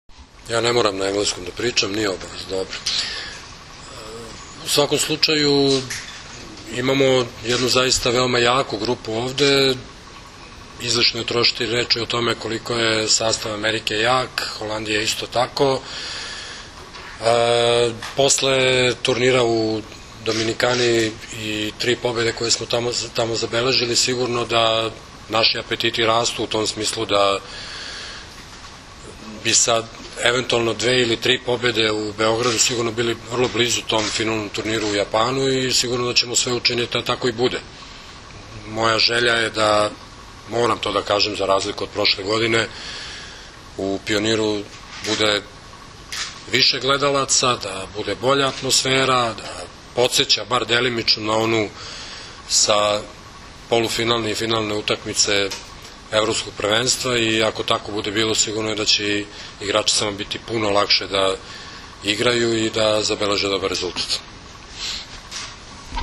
U beogradskom hotelu “M” danas je održana konferencija za novinare povodom predstojećeg turnira F grupe II vikenda XXI Gran Prija 2013.
IZJAVA